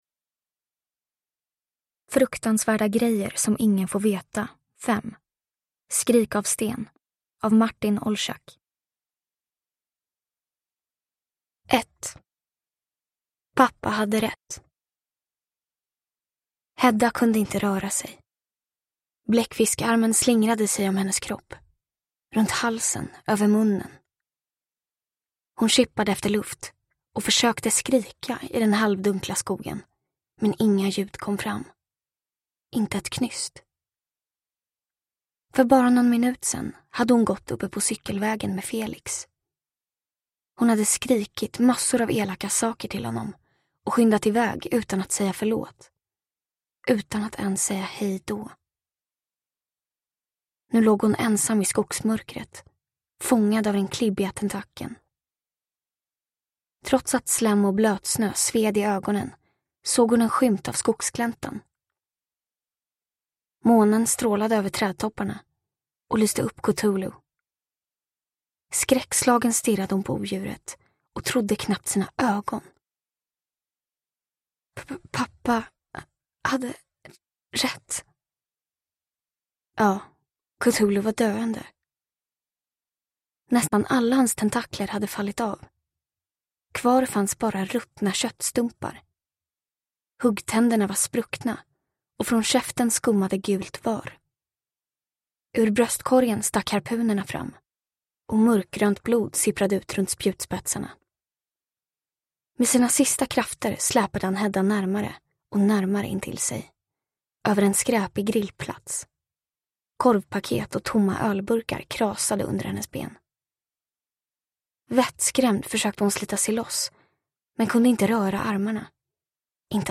Skrik av sten – Ljudbok